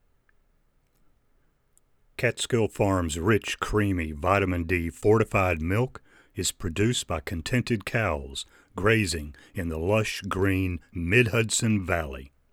Sample WAV for ACX - Produce a Simple Audacity Forum Voice Test
If I can impose once again, here’s the recording using the Yeti:
Same parameters as before except I moved the laptop into the adjacent space so it’s not quite so close.